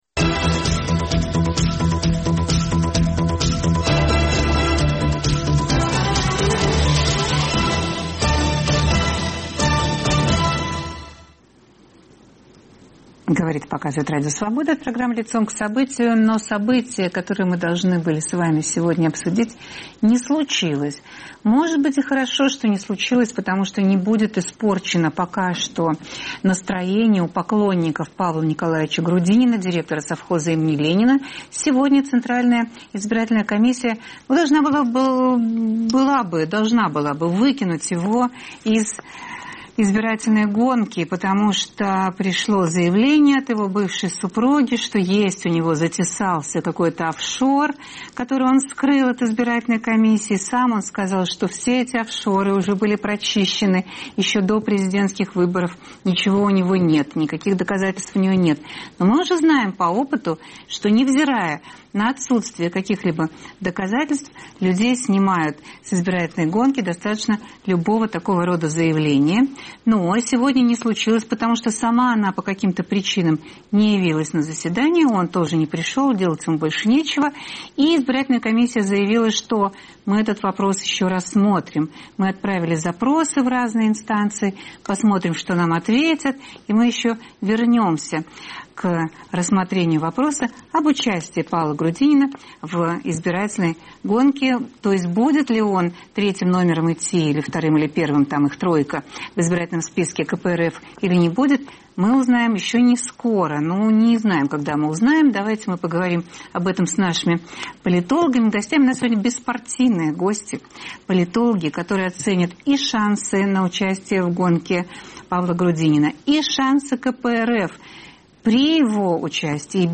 Как поведет себя КПРФ, если директора совхоза имени Ленина все ж таки не подпустят к выборам в Думу? Обсуждают политологи Станислав Белковский и Аббас Галлямов.